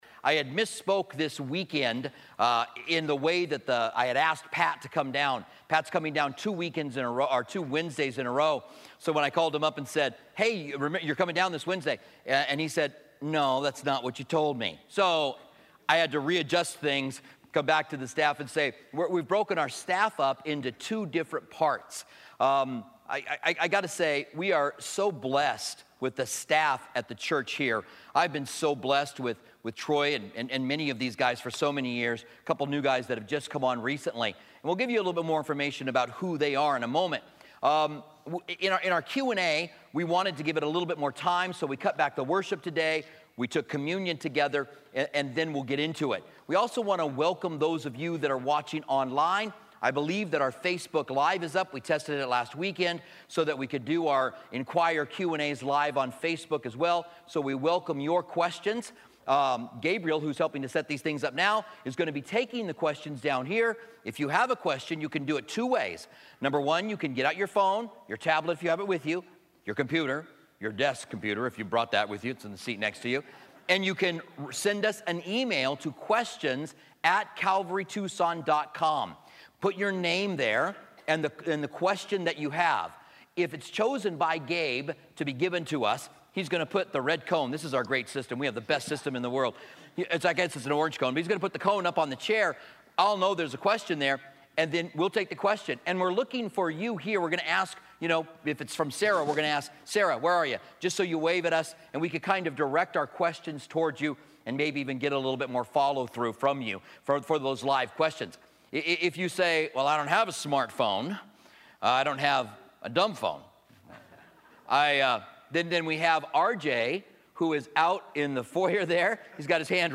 Inquire Q&A - Session 2 - Staff Panel A - East Campus Sep 7, 2016 · Multiple Listen to a pannel of our pastors give their answers for questions submitted to the Inquire Q&A series.